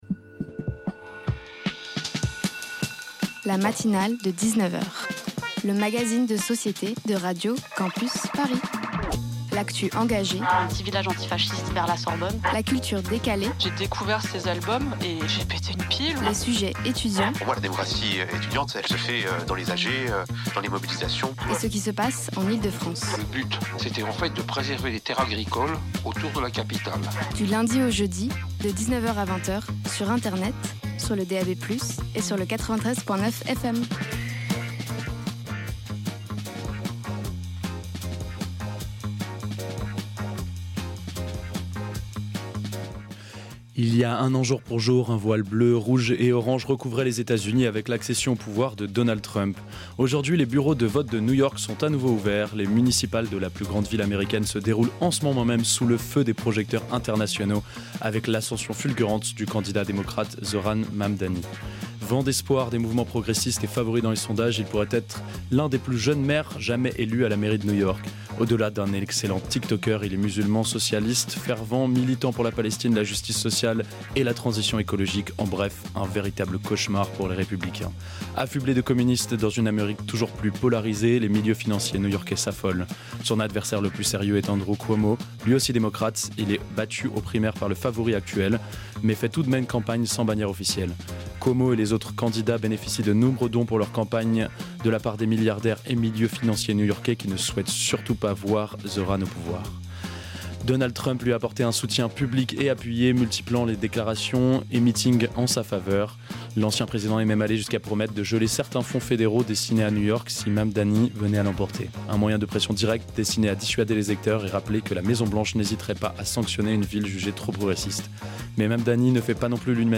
Tous les soirs, des reportages pas chiants, des chroniques épiques et des interviews garanties sans conservateur viendront ponctuer cette heure où l’ennui subit le même sort que Bonaparte : le bannissement.